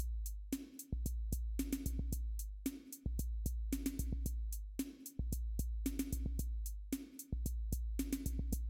鼓点套件循环1与帽子
My Drumstep Kit loops are drum loops for dubstep or dance music etc.
Tag: 150 bpm Dubstep Loops Drum Loops 1.46 MB wav Key : Unknown